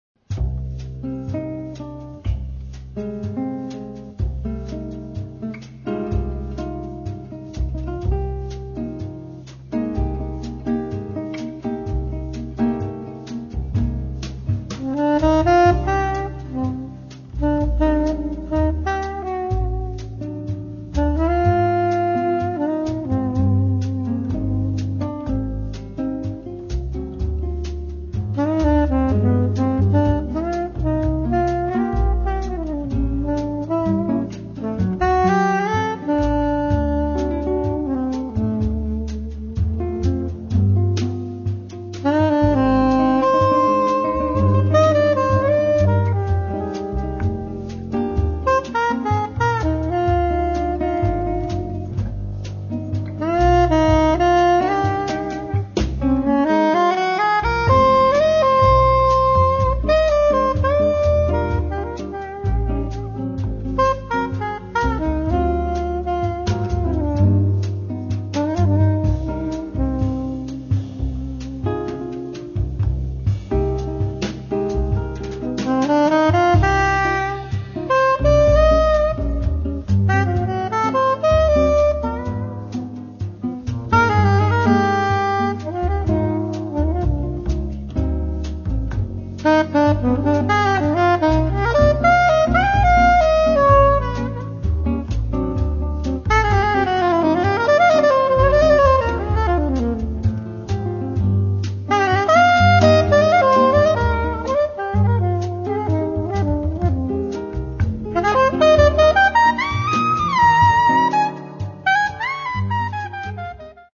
soprano and tenor sax
drums
acoustic guitar
double bass